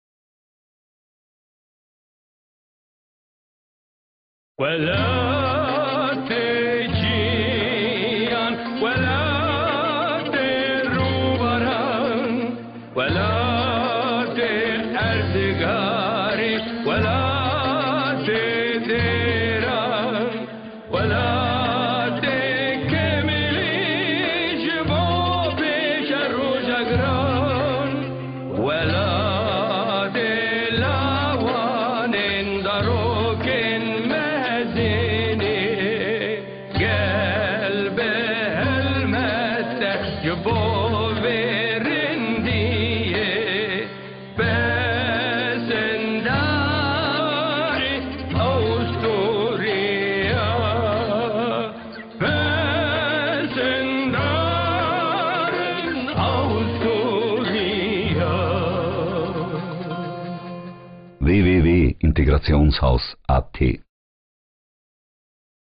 Победители Golden Drum 2008 - Радио